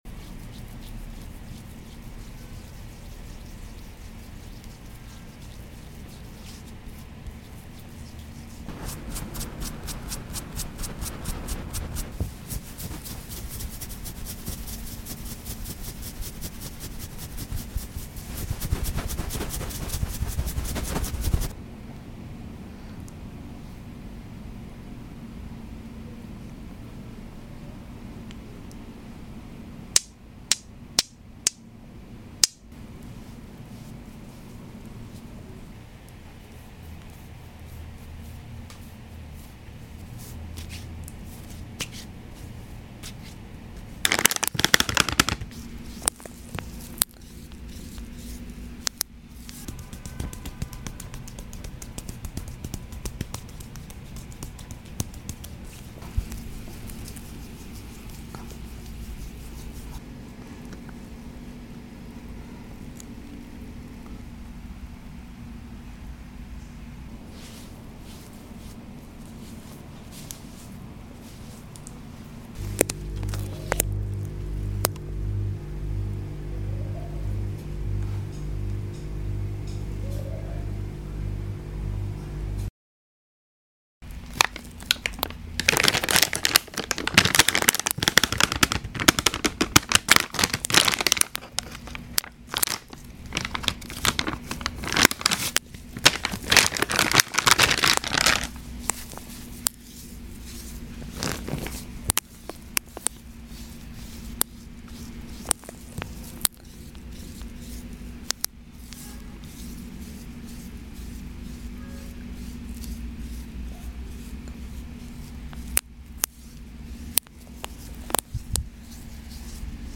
ASMR💫 Aggressive Hair scalp scratch sound effects free download
ASMR💫 Aggressive Hair scalp scratch massage | face massage with ice block & jade roller